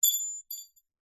Звуки гвоздей, шурупов
Звук упавшего металлического предмета на твердую поверхность